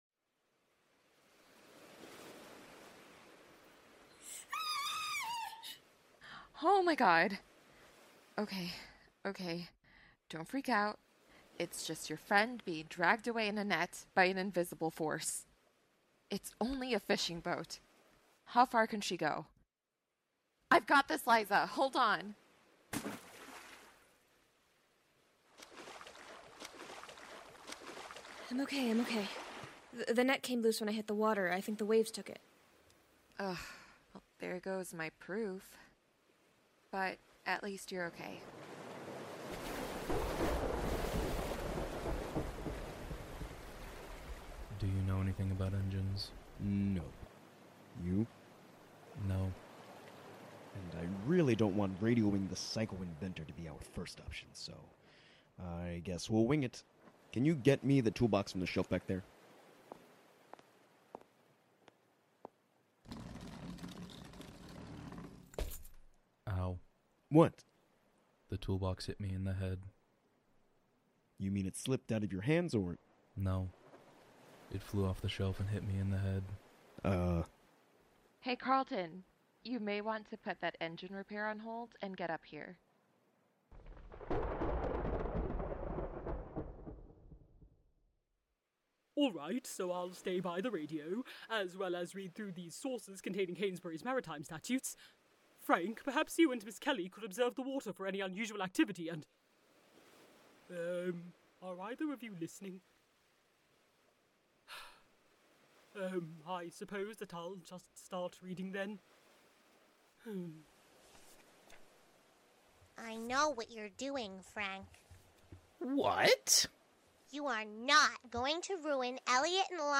*Disclaimer - The first 6 episodes of our show were when we were still finding our audio footing and our voice; we are a living production, after all!